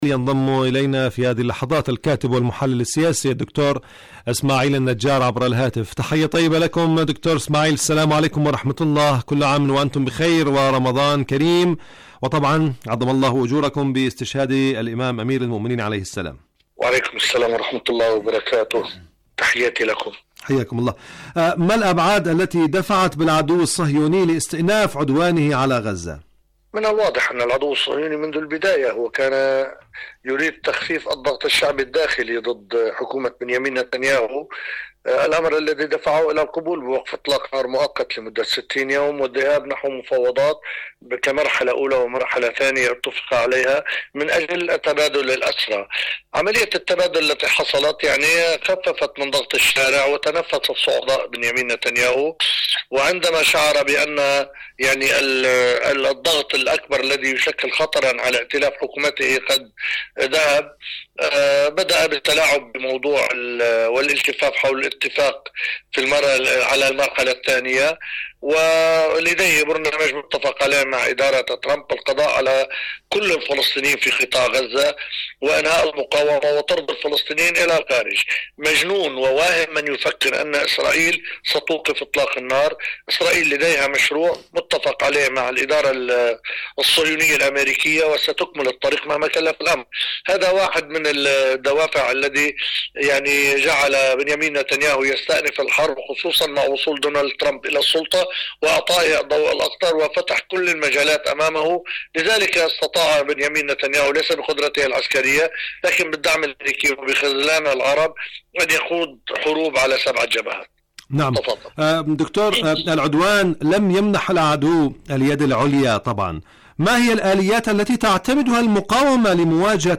مقابلات